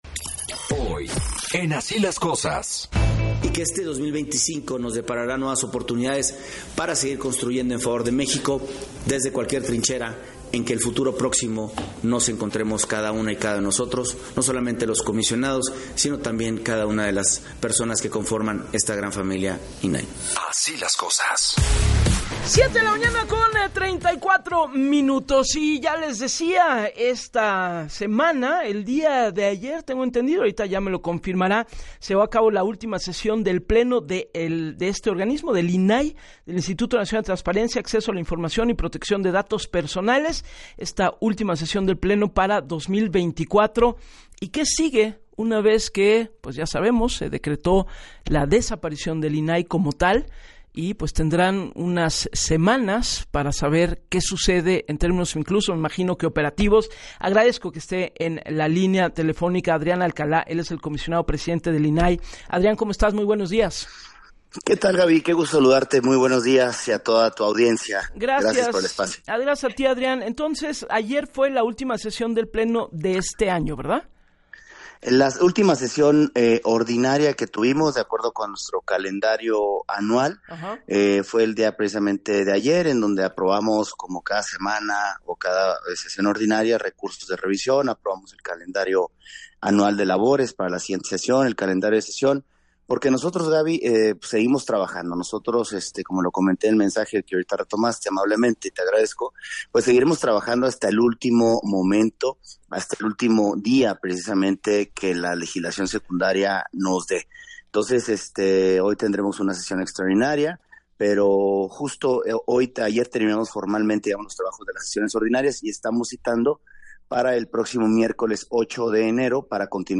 En entrevista con Gabriela Warkentin el comisionado presidente del Instituto Nacional de Transparencia, Acceso a la Información y Protección de Datos Personales (INAI), Adrián Alcalá, informó que se realizó la última sesión del año y que seguirán trabajando hasta el último momento que la legislación secundaria se los permita, ayer en sesión ordinaria aprobaron el calendario anual de actividades 2025.